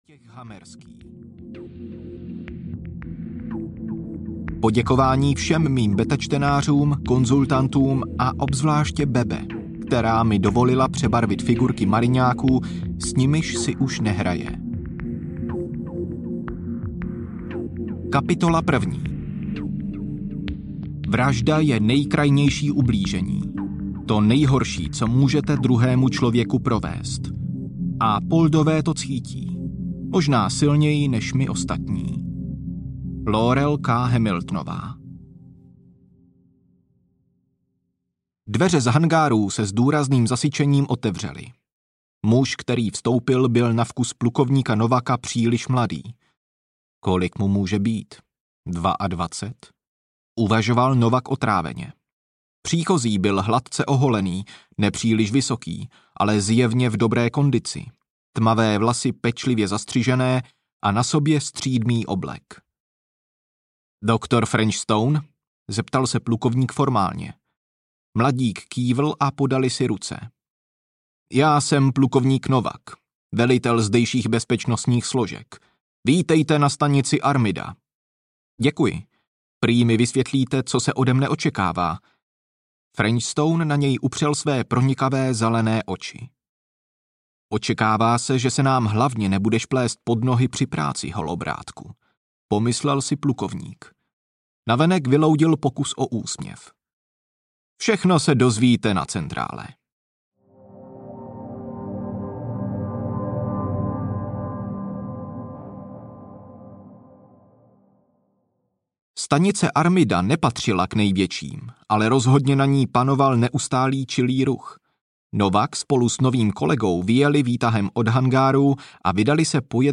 Stanice Armida audiokniha
Ukázka z knihy